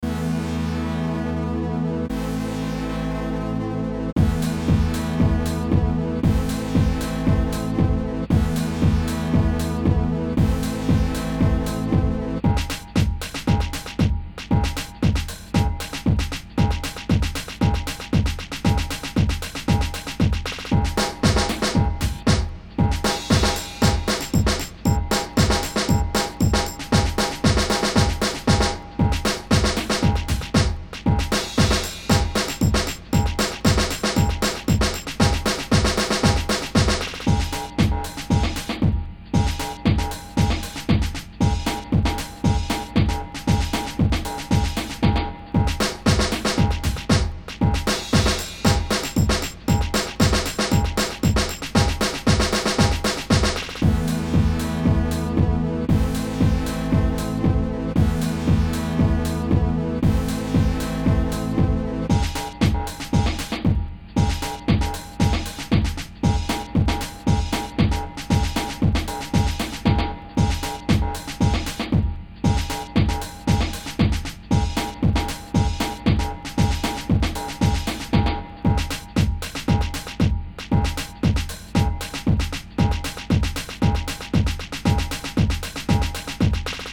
Home > Music > Ambient > Electronic > Dreamy > Running